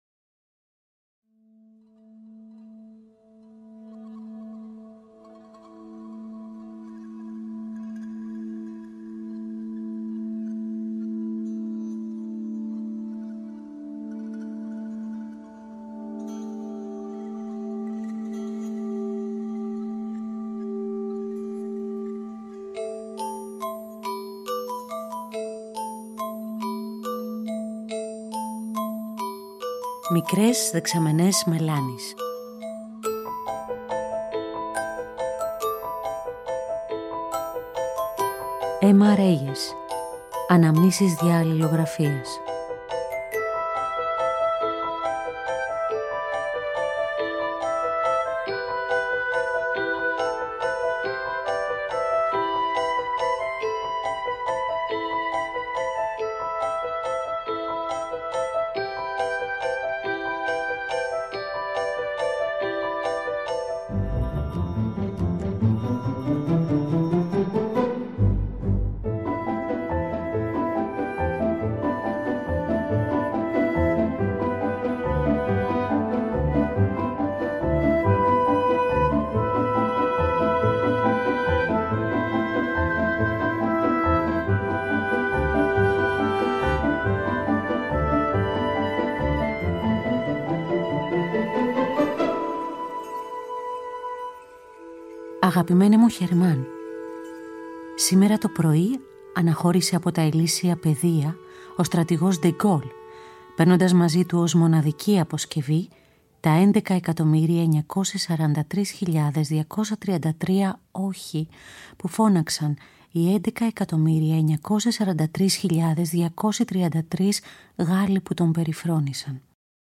Στη ραδιοφωνική μεταφορά μας, στο ηχητικό τοπίο μυστικισμού κι εγκλεισμού του μοναστηριού , αποδίδονται κάποιες επιστολές που έστειλε αργότερα στον φίλο της Χερμάν Αρσινιέγας .